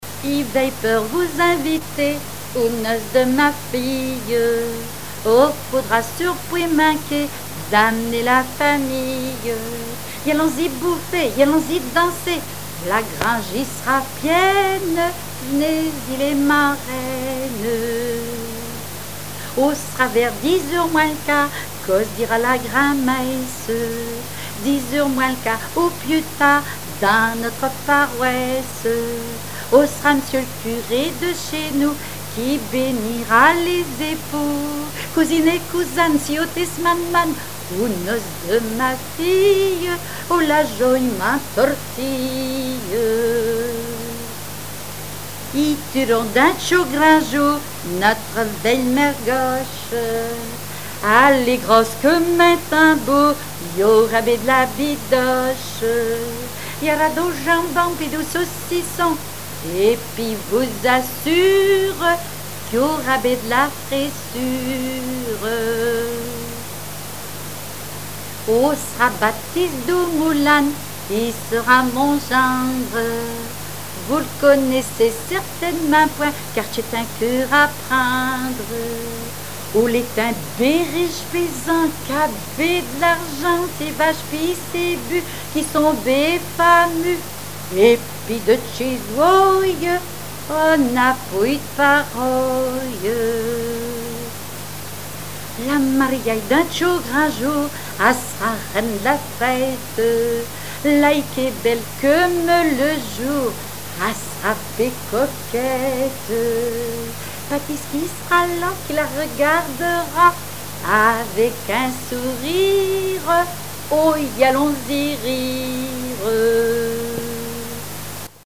Patois local
répertoire de chansons populaire et traditionnelles
Pièce musicale inédite